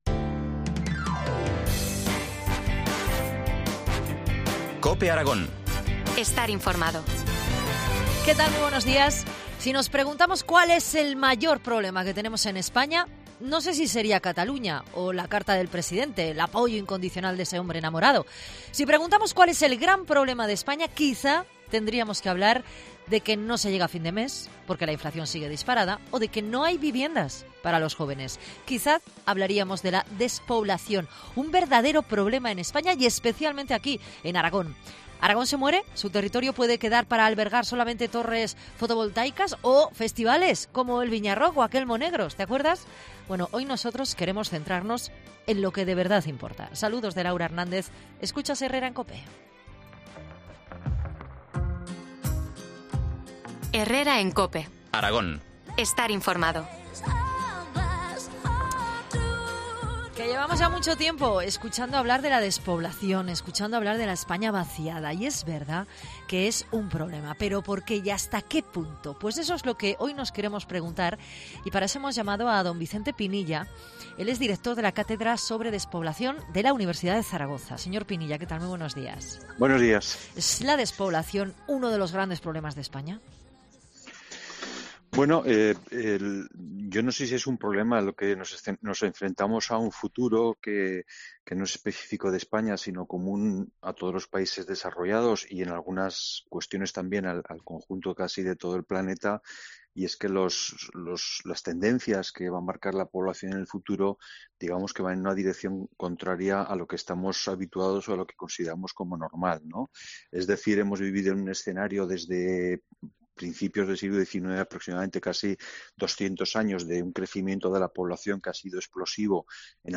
Entrevista del día en COPE Aragón